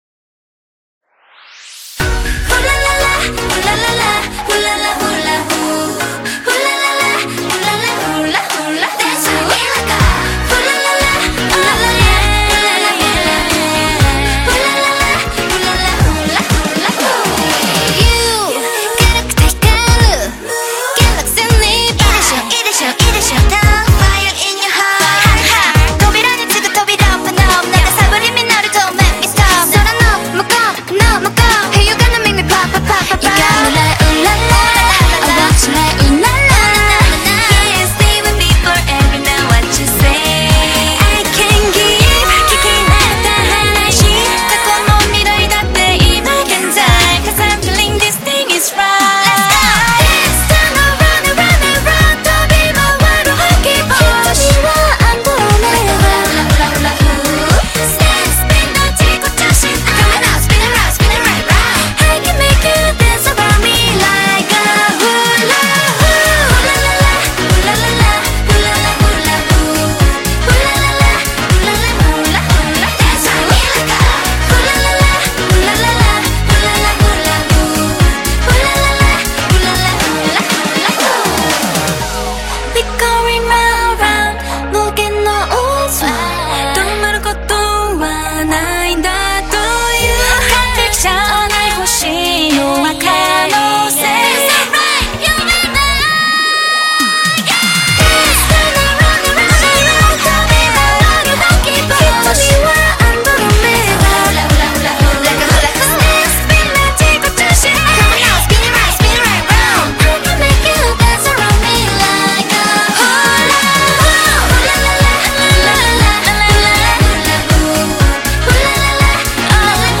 BPM120
Audio QualityMusic Cut
ComentariosTime for some k-pop!